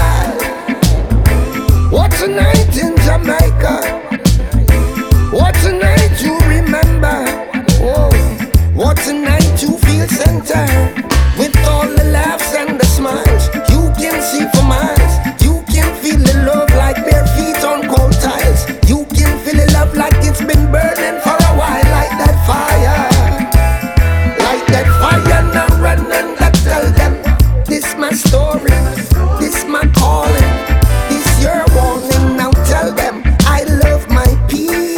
Off-beat гитары и расслабленный ритм
Reggae Jazz Crossover Jazz
Жанр: Джаз / Регги